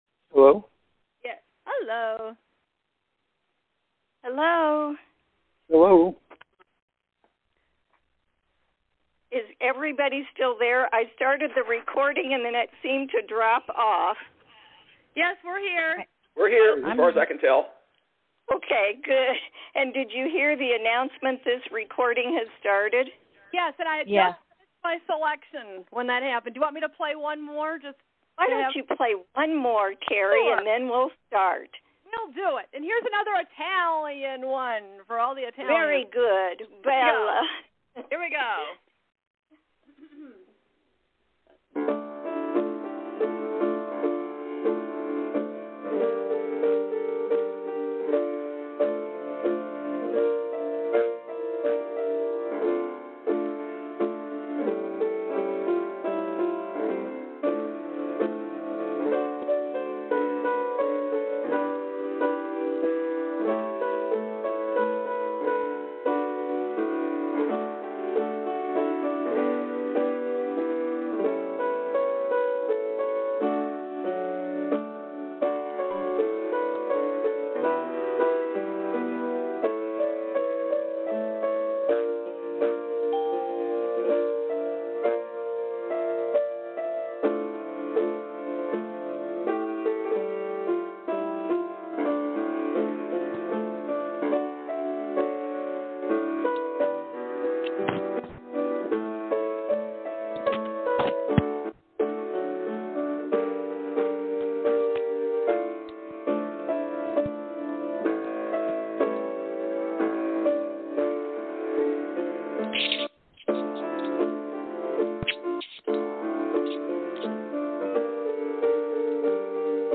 2020-Poetry-Luncheon-Audio.mp3